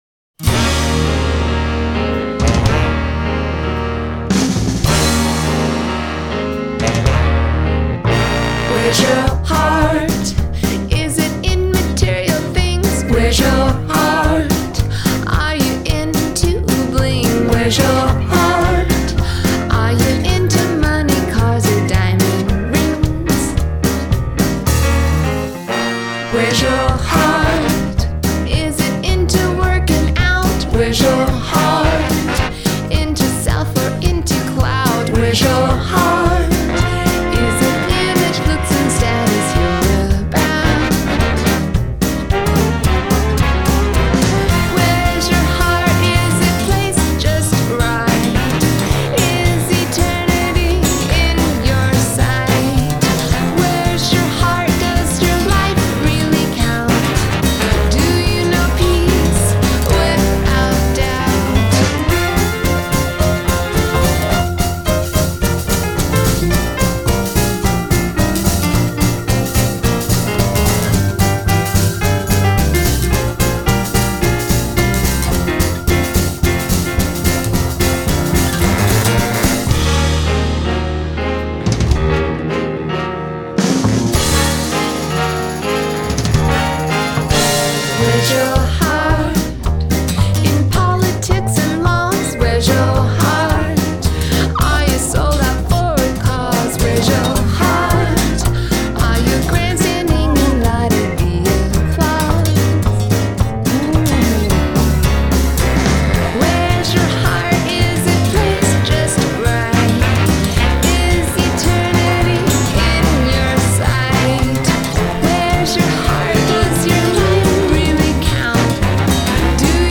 Adult Contemporary
Gospel
Indie Pop , Musical Theatre